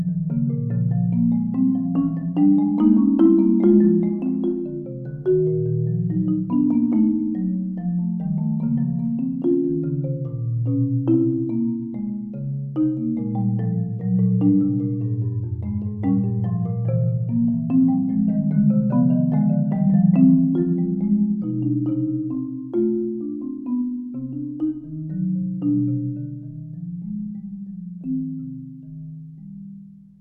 Bach au marimba